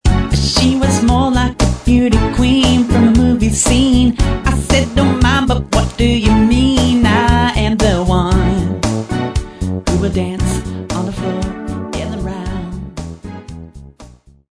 Samples Of Cover Tunes With Vocals